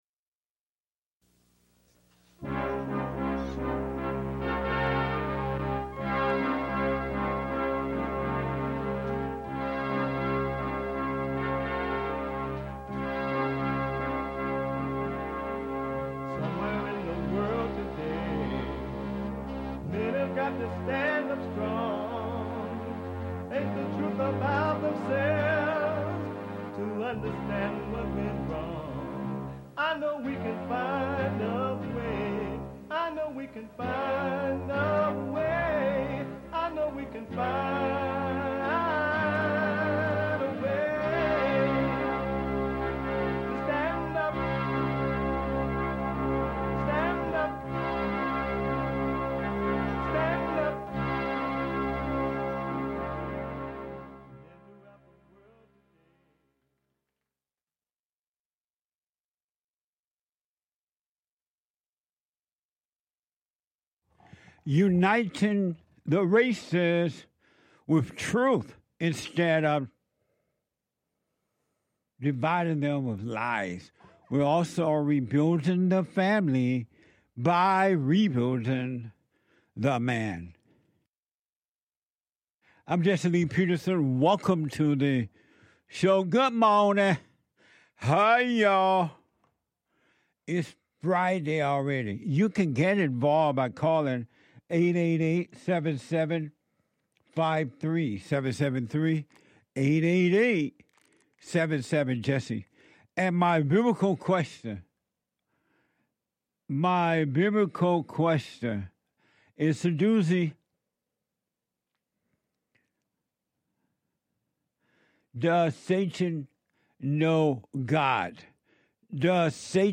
The Jesse Lee Peterson Radio Show